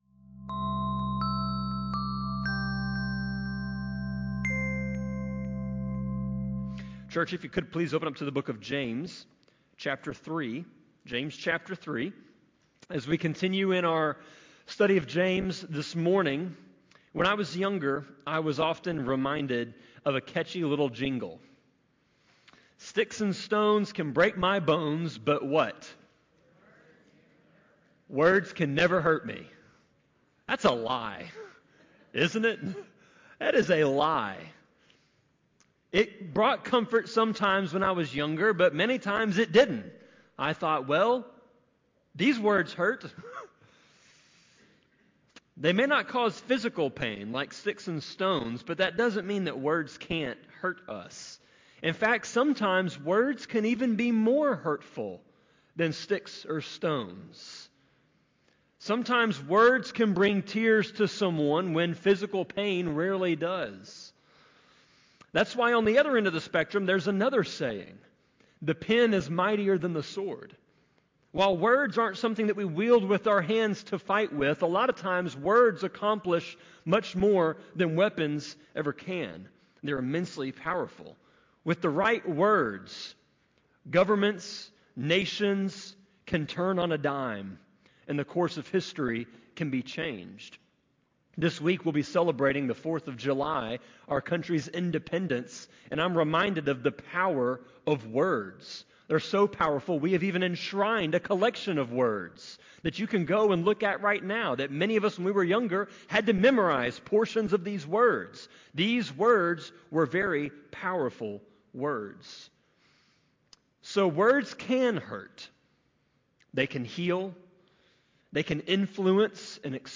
Sermon-25.6.29-CD.mp3